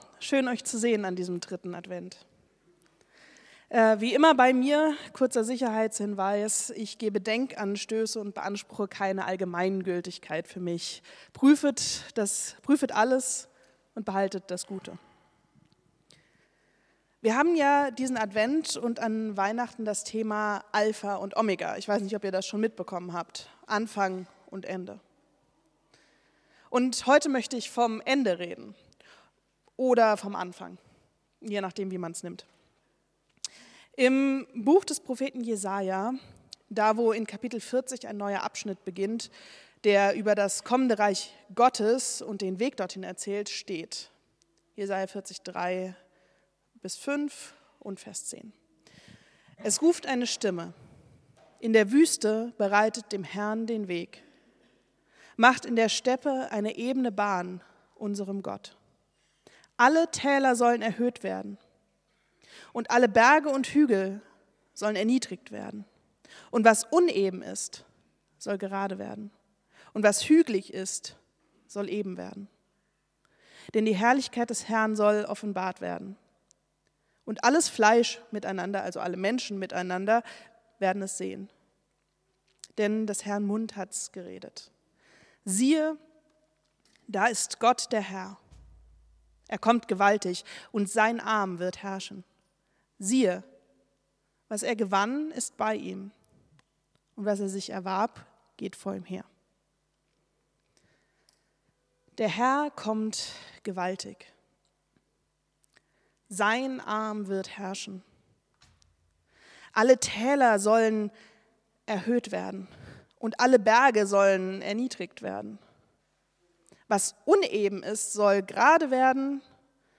Predigt vom 14.12.2025